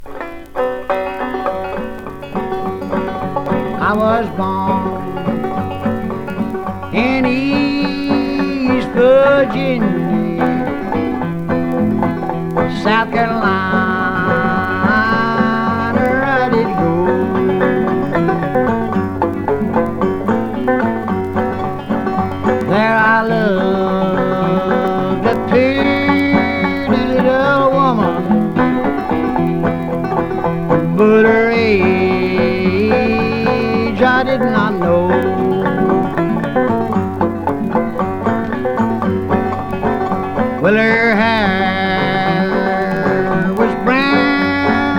Folk, Country, Appalachian Music, Bluegrass　USA　12inchレコード　33rpm　Mono